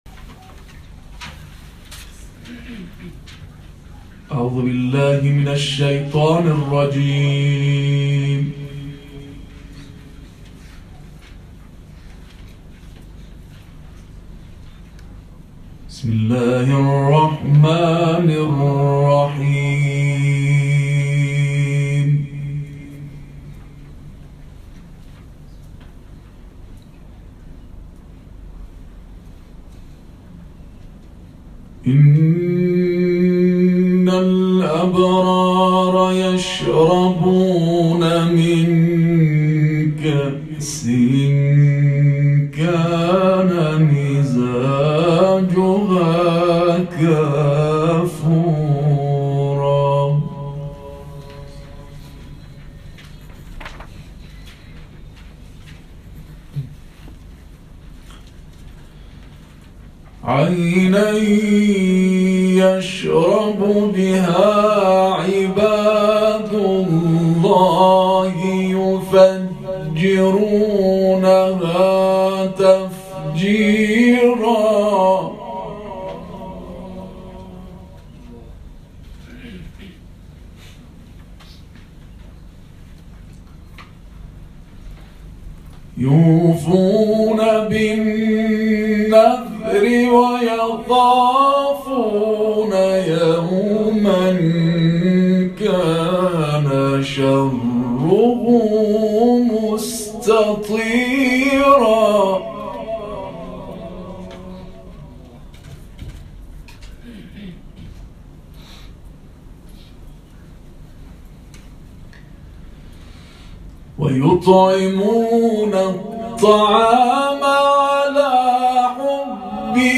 گروه فعالیت‌های قرآنی: محفل انس باقرآن کریم، دیروز پنجم تیرماه، در ساختمان مرکزی بانک انصار برگزار شد.